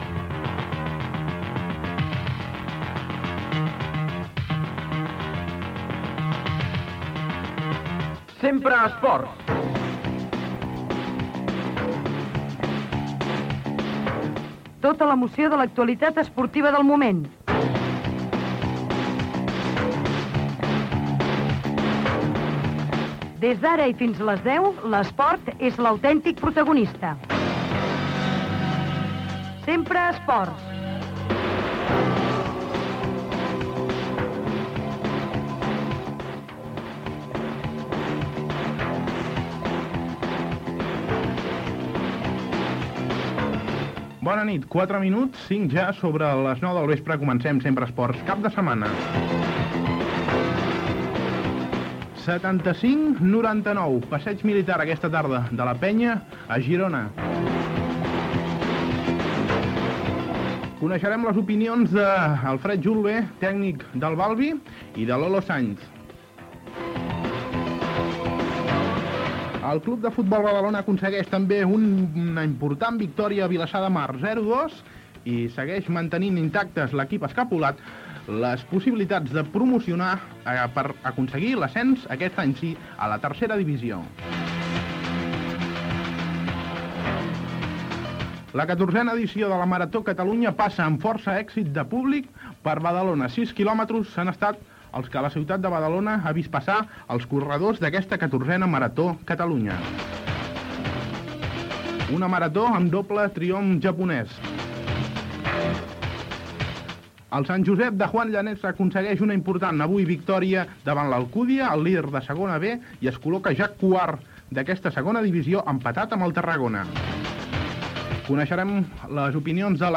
Careta i inici del programa amb el sumari dels continguts, publicitat, resultat del Girona-Penya de bàsquet.
Esportiu
FM